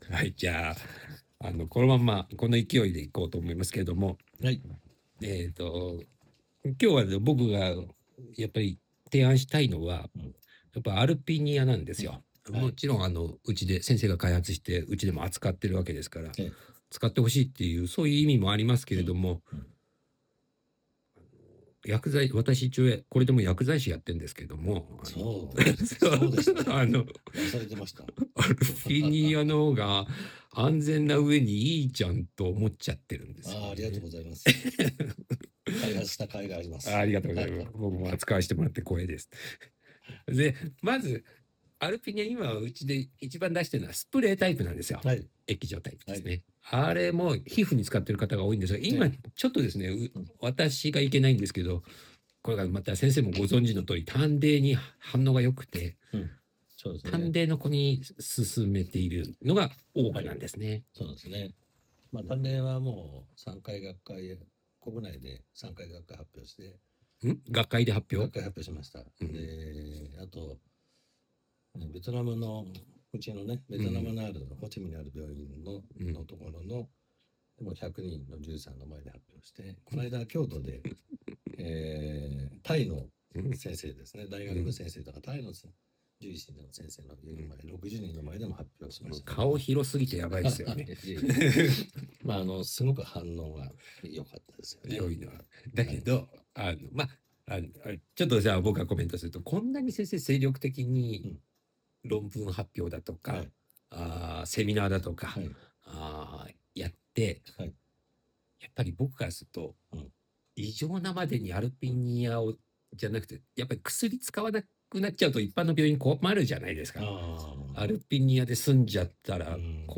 【皮膚ケア・対談音声つき④】アルピニアの本音と余談